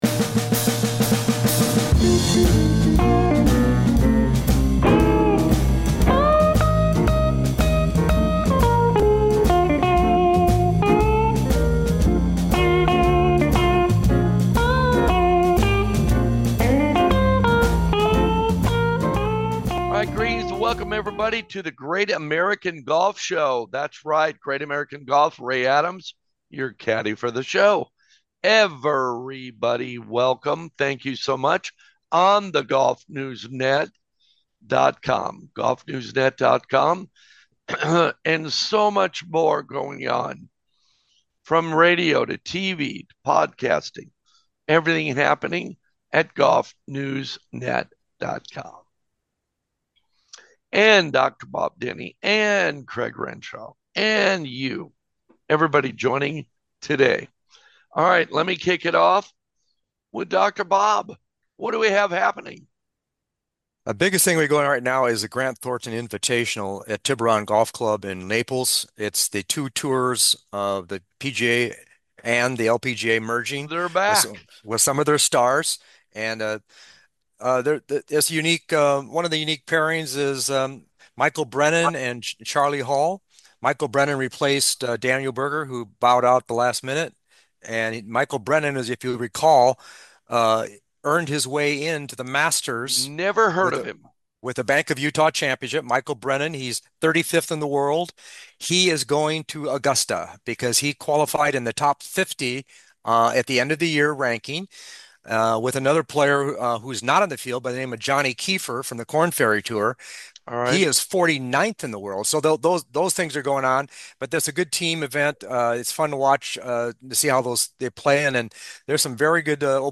This golf talk show features great co-hosts, players and leading golf industry guests.